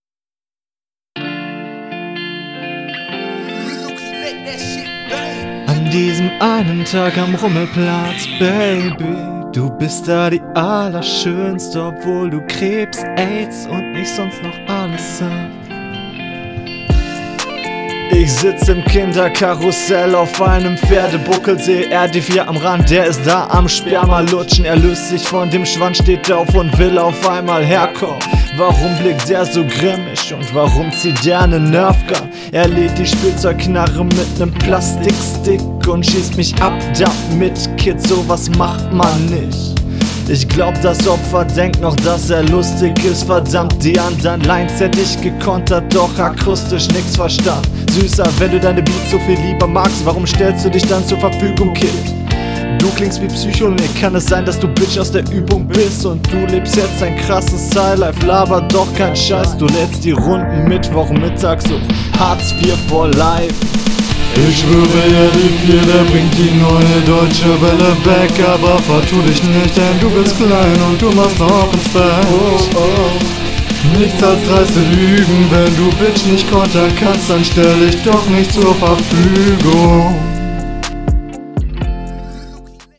das intro ist sehr unangenehm und nicht im takt, im part zeigst du dann aber …
Flowlich sehr solide, Stimmeinsatz echt cool, ein paar Variationen sind dabei und auch etwas mehr …